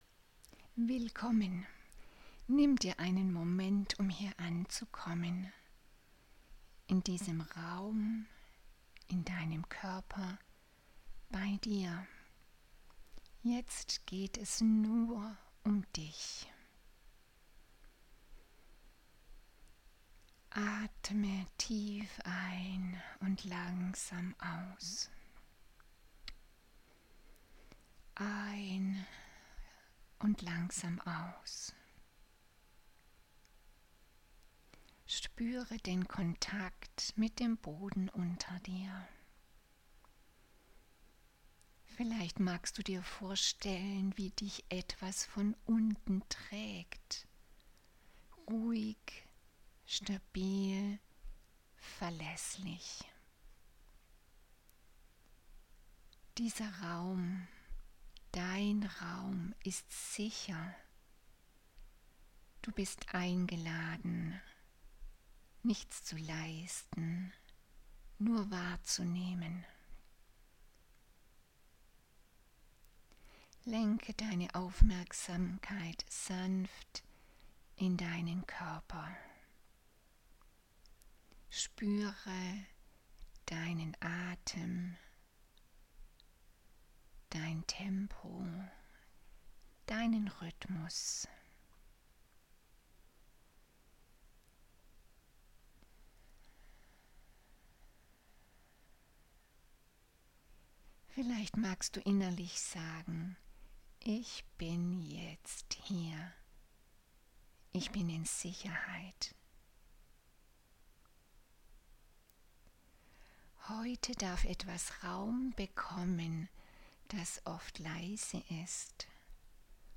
Lass dich von der geführten Meditationen inspirieren,
Meditation-zum-Jahreswechsel.mp3